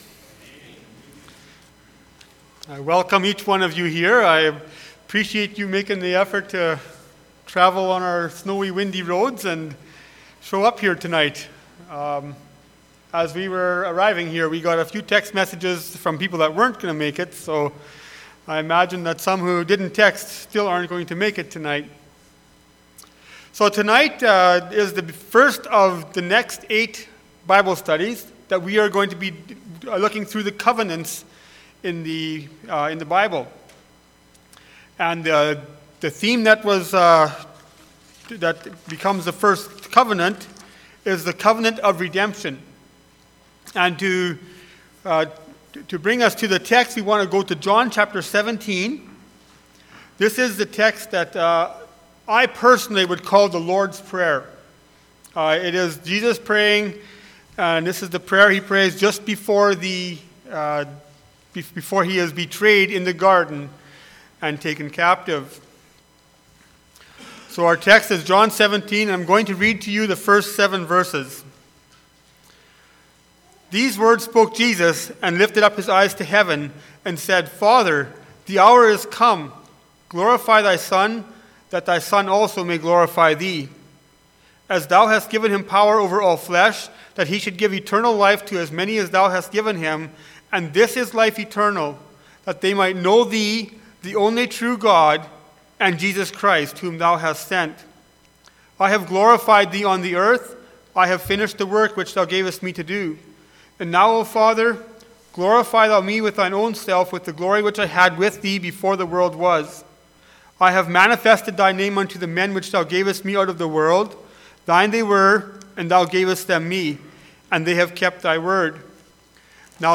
Passage: Psalm 2, John 17:1-7 Service Type: Church Bible Study